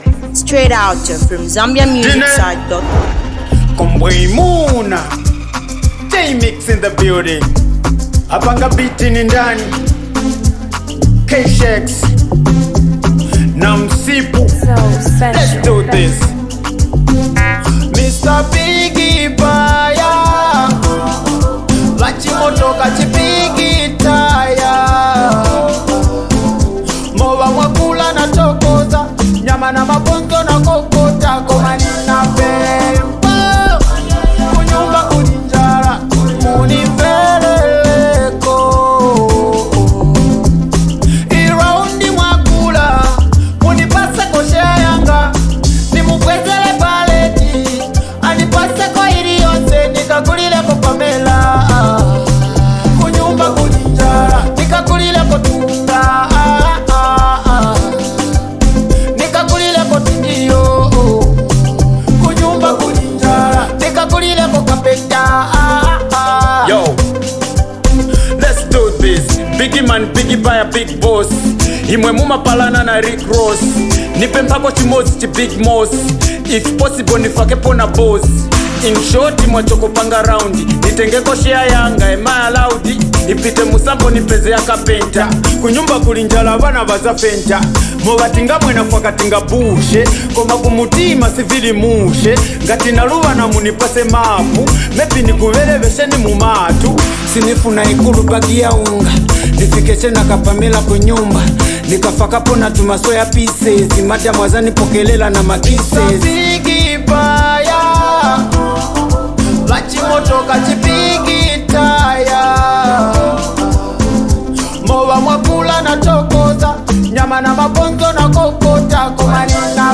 awesome hot beer banger
it’s a nice song with a great vibes